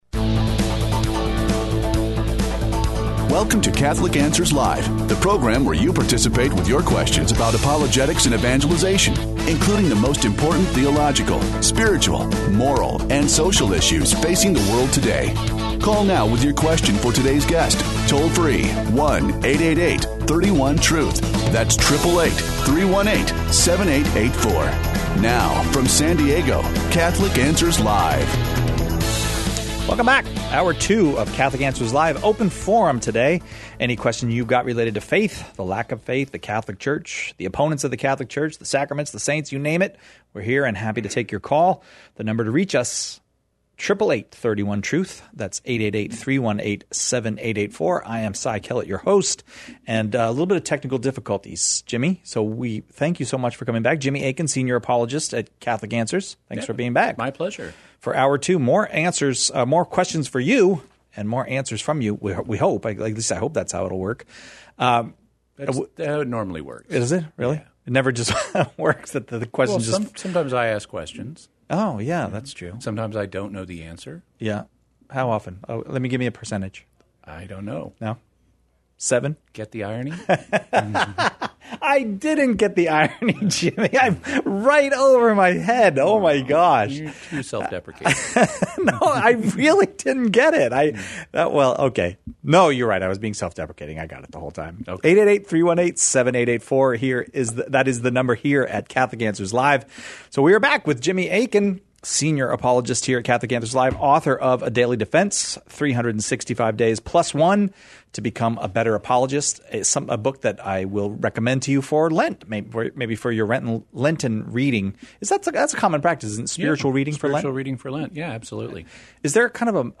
The callers choose the topics during Open Forum, peppering our guests questions on every aspect of Catholic life and faith, the moral life, and even philosophical topics that touch on general religious belief. Questions Covered: Why do Catholics pray to Mary?